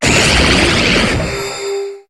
Cri de Mandrillon dans Pokémon HOME.